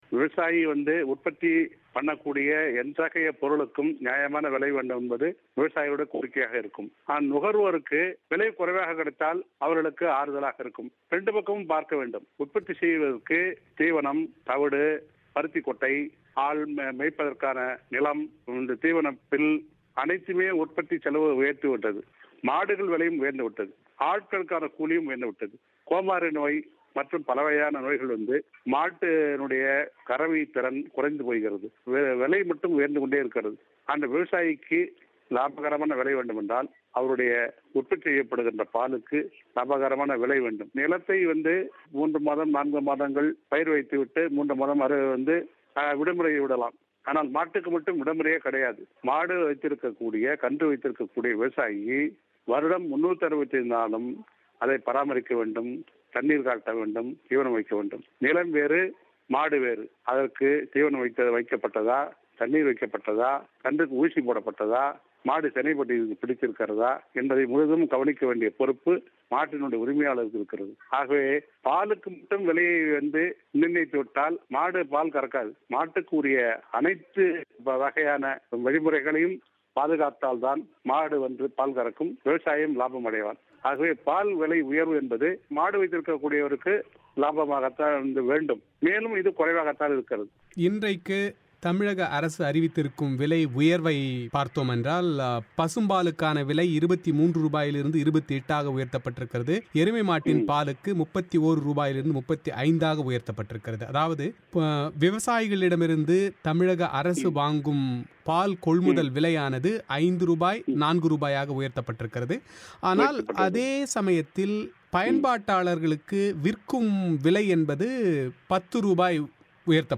வழங்கிய செவ்வி.